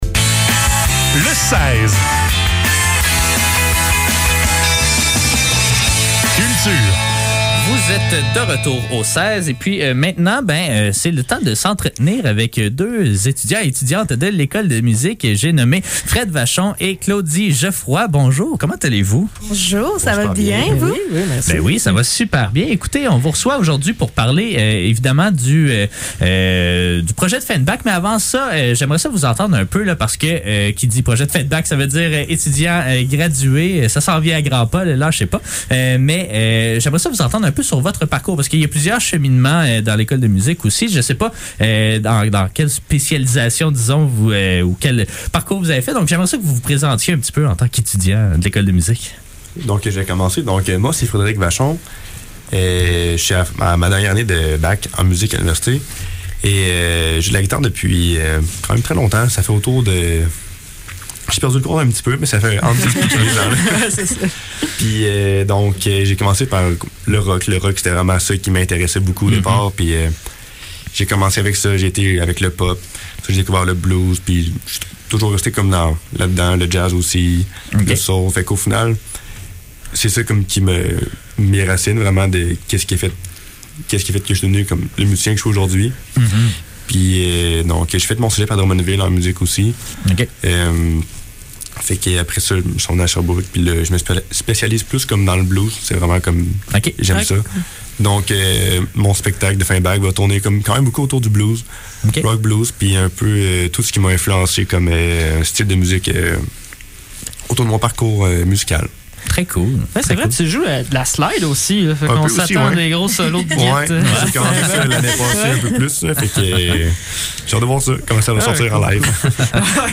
Le seize - Entrevue et performance live